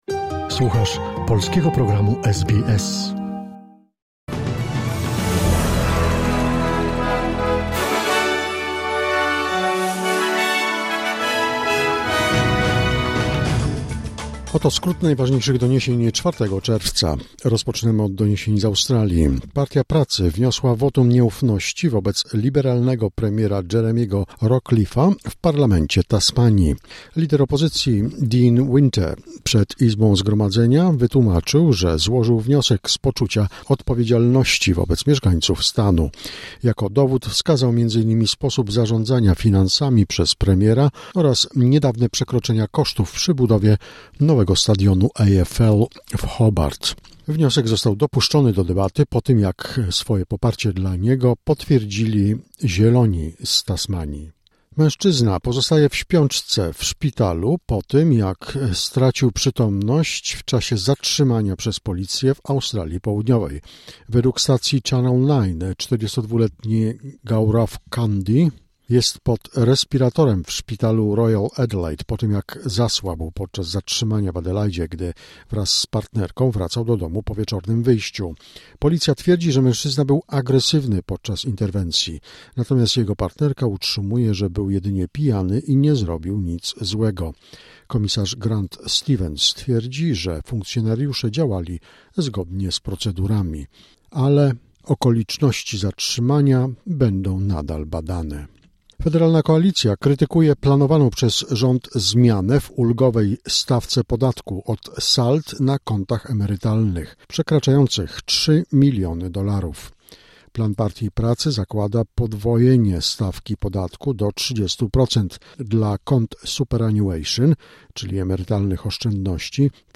Wiadomości 4 czerwca SBS News Flash